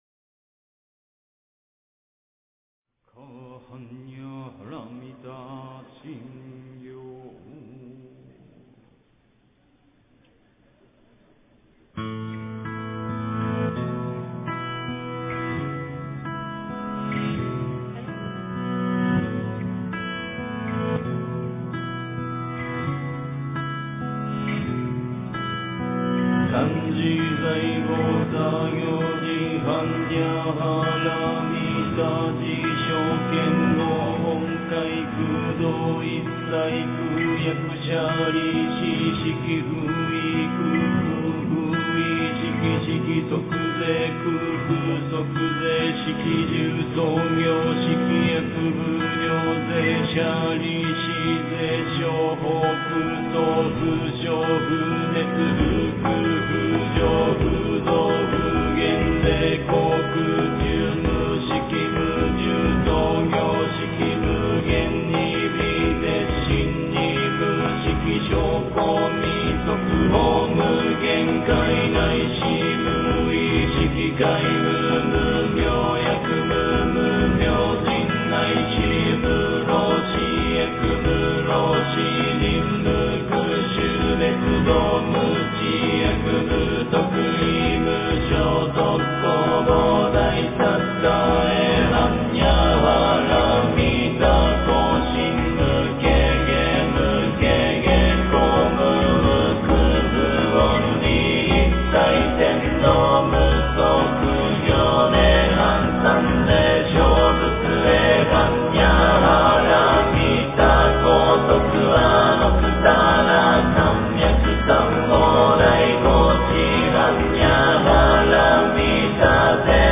诵经
佛音 诵经 佛教音乐 返回列表 上一篇： 大悲咒 下一篇： 心经 相关文章 大白伞盖佛母咒--群星 大白伞盖佛母咒--群星...